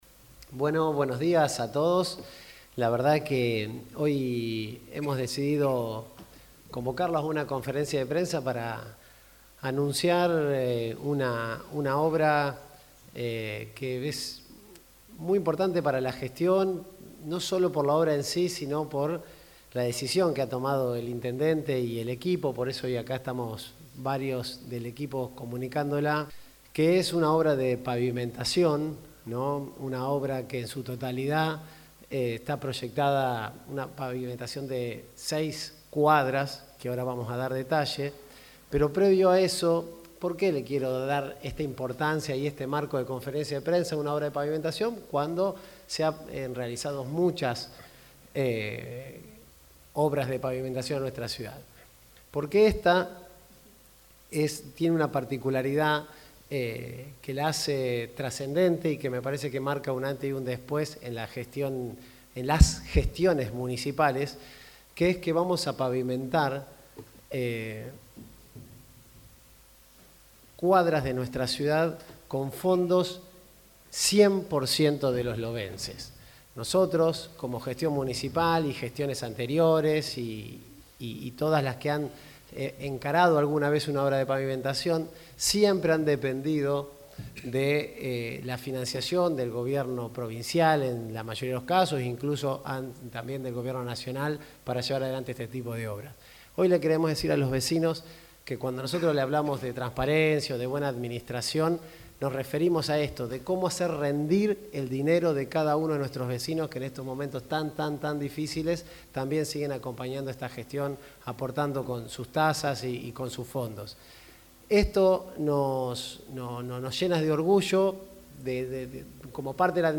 El anuncio formal se realizó en la sala de actos del Palacio Municipal, donde el Secretario de Gobierno, Pablo Hasper, fue el encargado de detallar los alcances del plan. Un punto clave destacado por el funcionario fue la financiación de la obra: Hasper afirmó que la totalidad de los recursos necesarios provendrán de las arcas propias del municipio, asegurando así la autonomía y la viabilidad del proyecto sin depender de fondos externos.